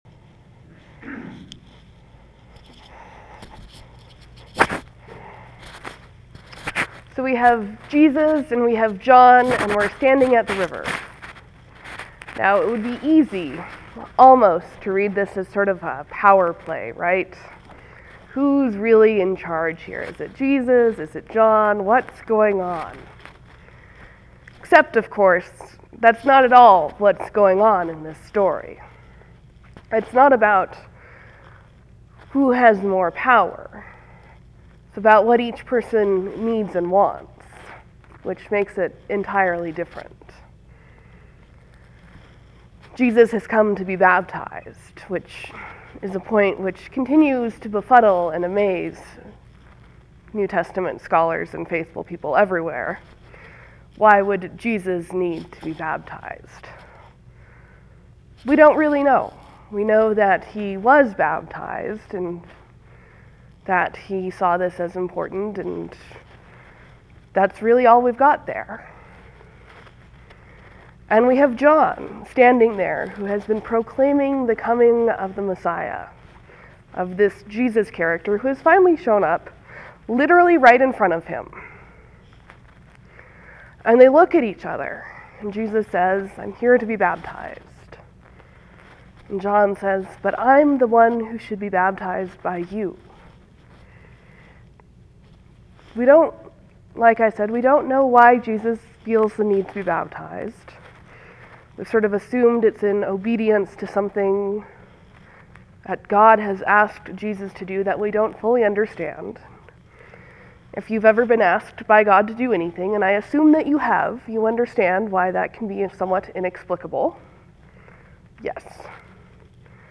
A sermon on baptism